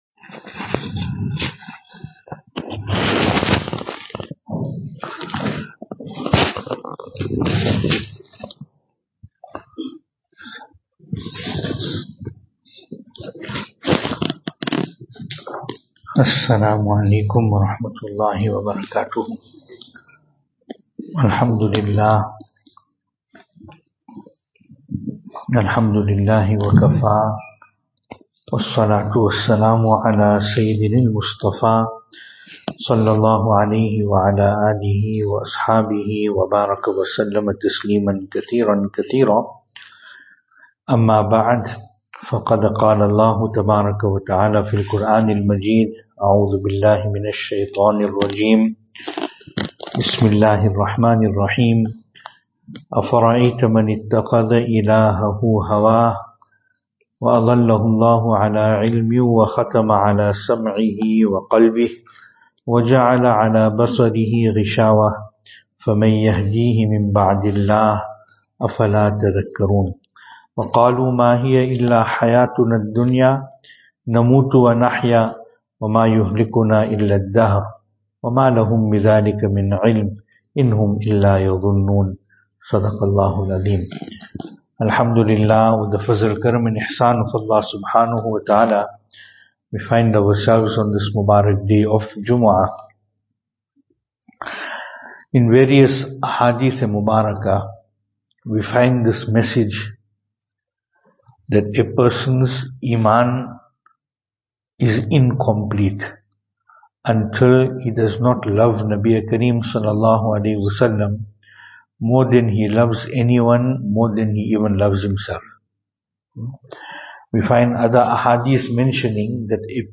2025-07-11 Have you seen the one who takes his whims as his diety Venue: Albert Falls , Madressa Isha'atul Haq Series: JUMUAH Service Type: Jumu'ah Topics: JUMUAH « Only Allah Ta’ala grants taufeeq to do good.